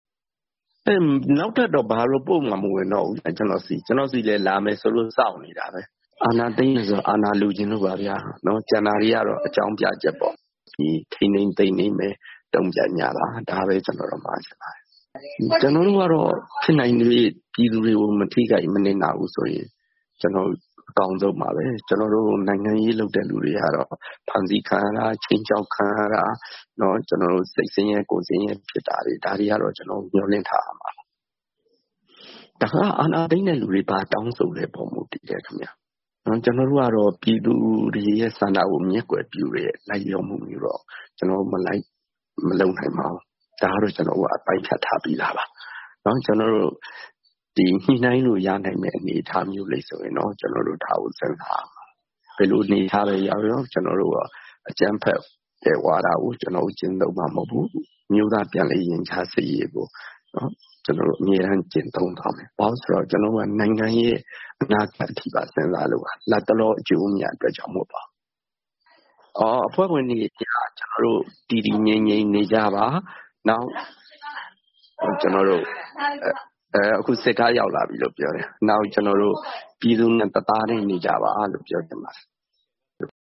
ဗွီအိုအေက တယ်လီဖုန်းနဲ့ ဆက်သွယ်မေးမြန်းချိန်